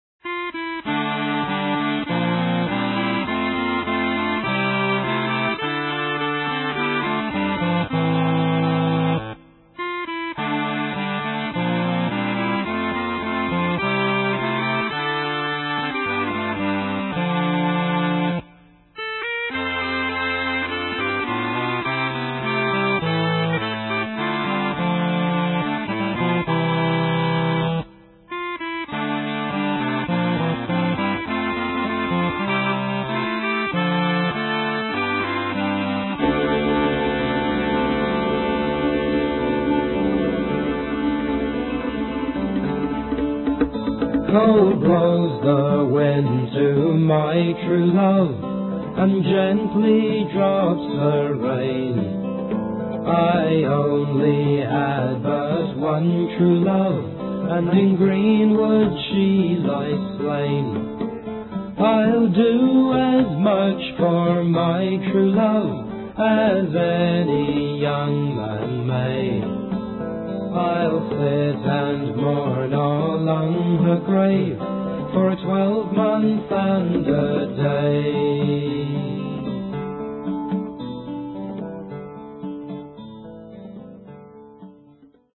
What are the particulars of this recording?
Mono, 1:24, 16 Khz, (file size: 166 Kb).